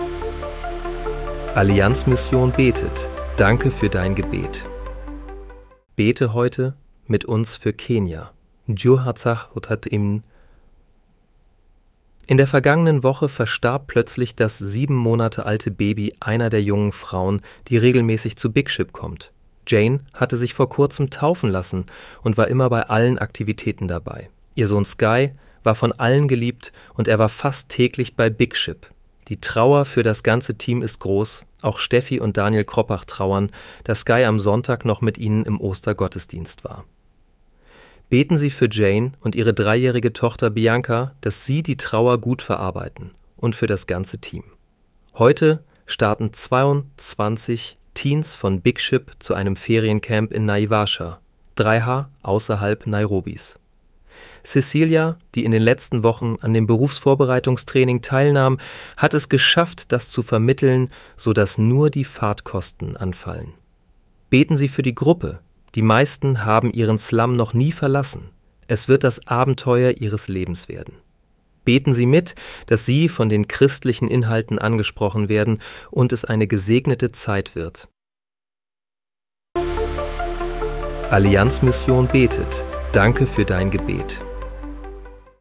Bete am 15. April 2026 mit uns für Kenia. (KI-generiert mit der